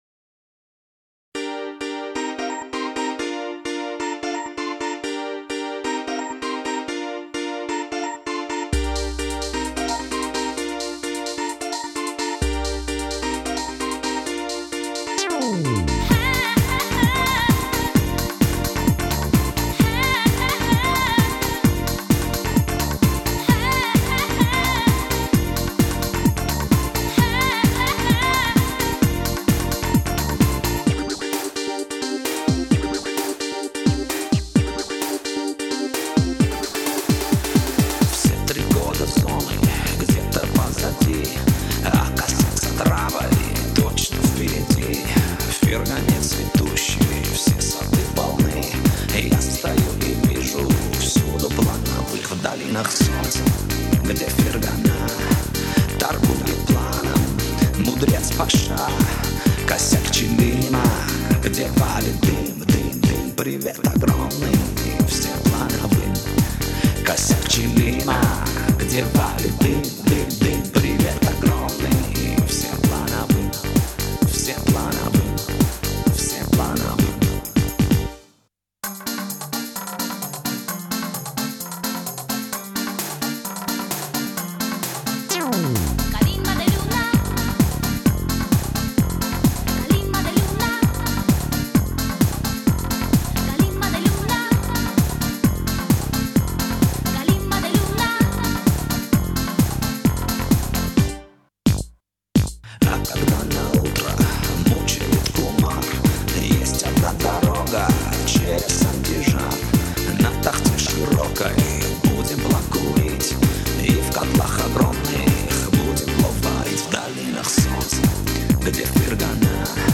best rabiz music from 1998